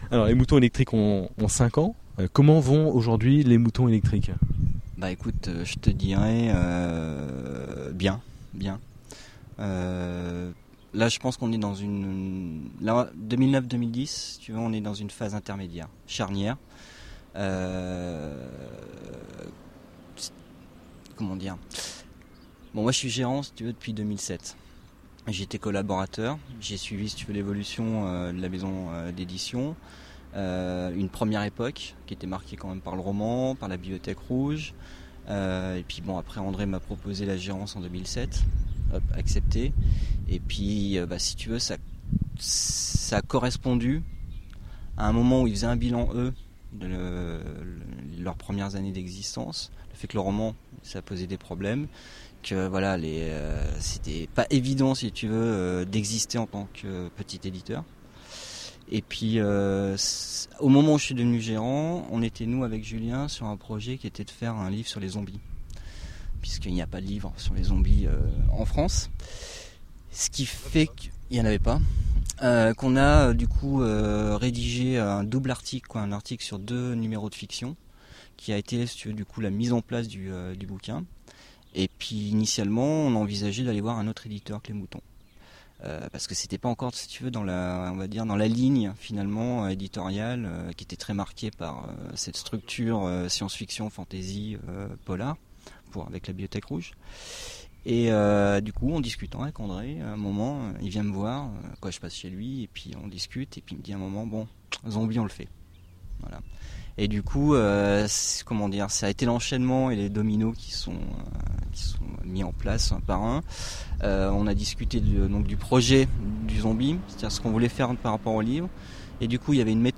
Attention, il y avait un peu de vent lors de l'enregistrement ce qui a nuit à la qualité du son.